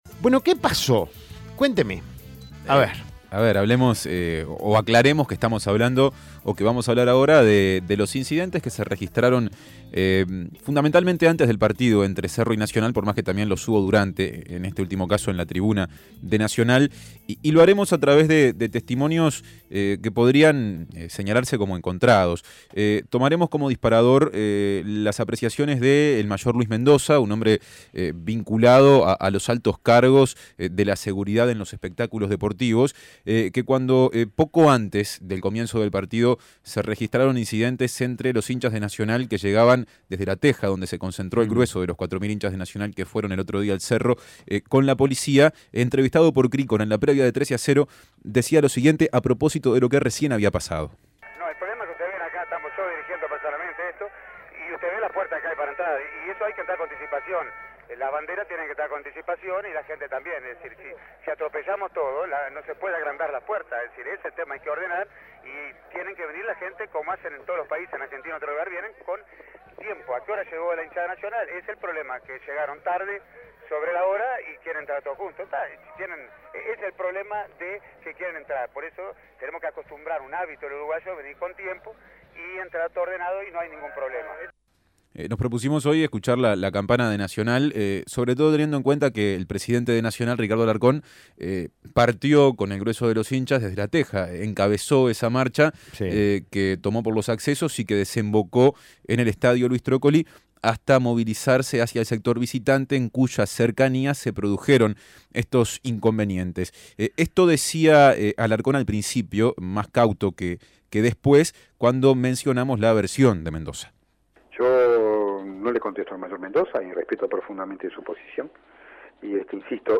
Testimonios